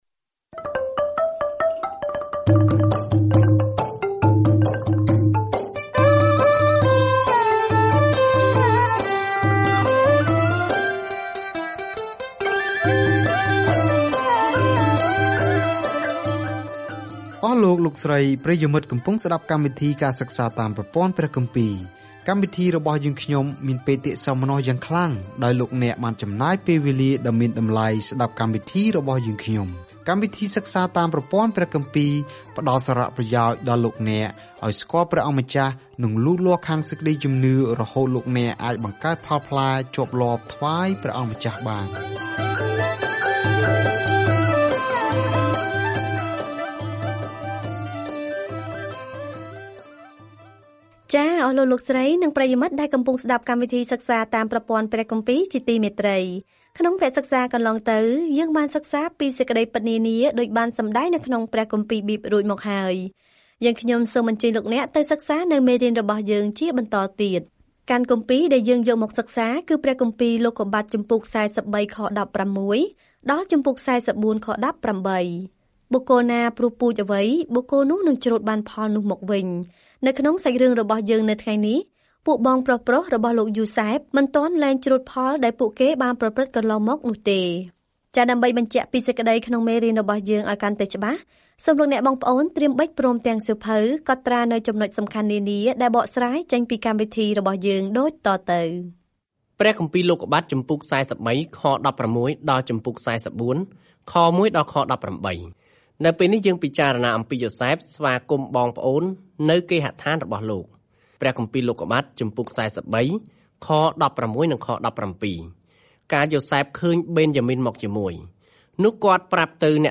ការធ្វើដំណើរប្រចាំថ្ងៃតាមរយៈលោកុប្បត្តិ នៅពេលអ្នកស្តាប់ការសិក្សាជាសំឡេង ហើយអានខគម្ពីរដែលបានជ្រើសរើសពីព្រះបន្ទូលរបស់ព្រះនៅក្នុងសៀវភៅលោកុប្បត្តិ។